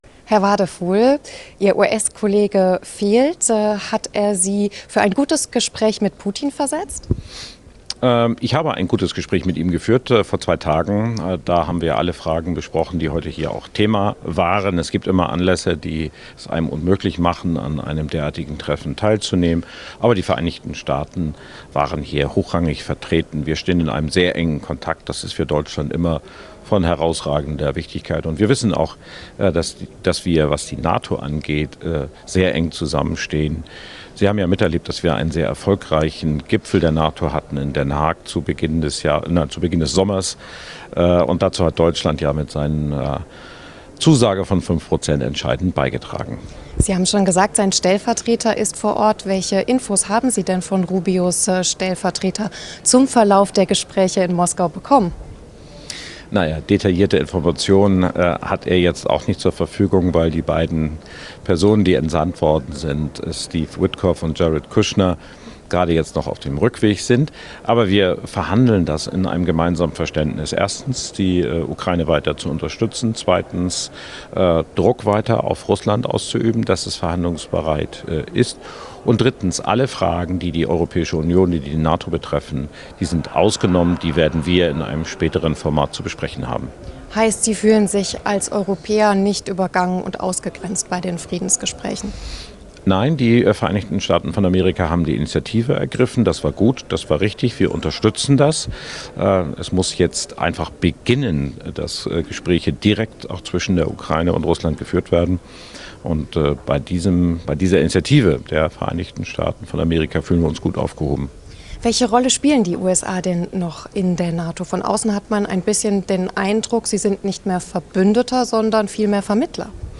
Johann Wadephul erklärt im Interview mit ntv, bei